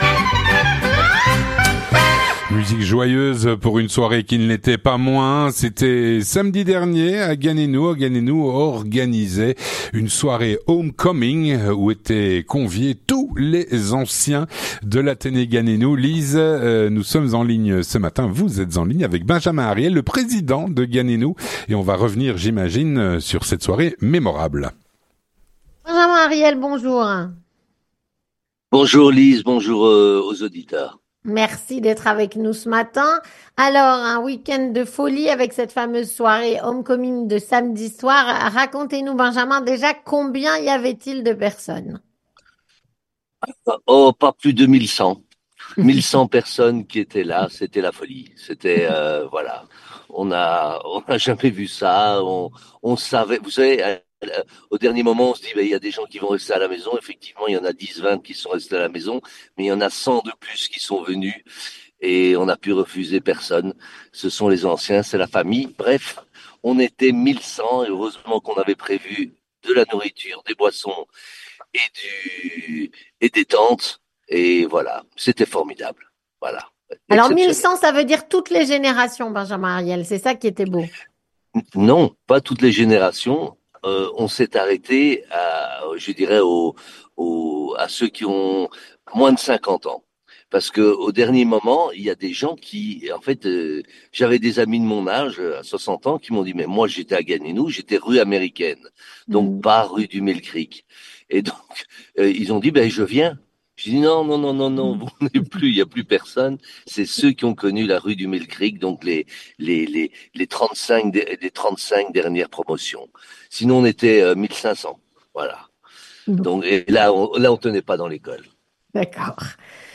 L'interview communautaire - Retour sur la soirée "homecoming" de l'école Ganenou.